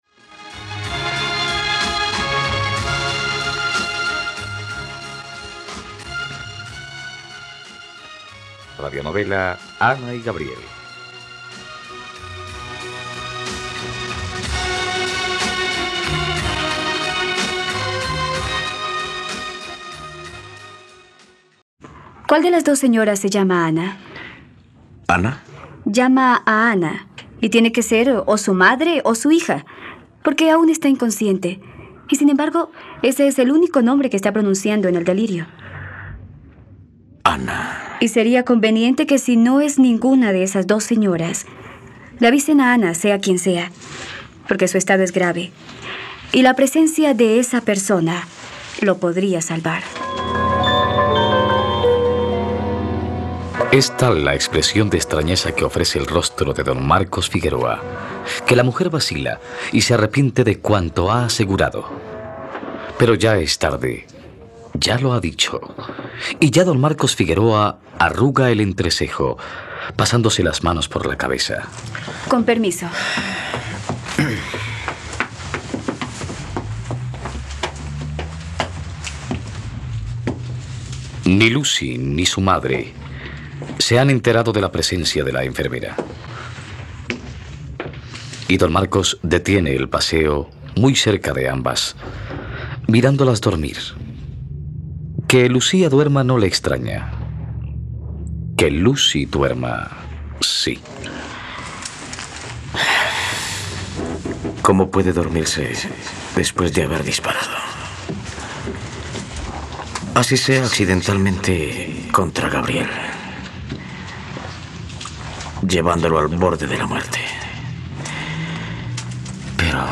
..Radionovela. Escucha ahora el capítulo 99 de la historia de amor de Ana y Gabriel en la plataforma de streaming de los colombianos: RTVCPlay.